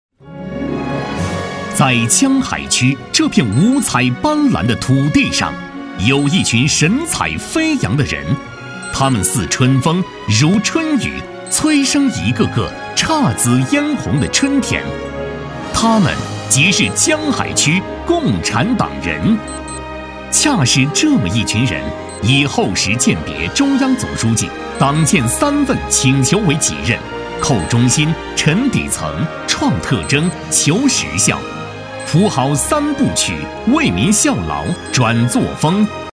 配音风格： 磁性，年轻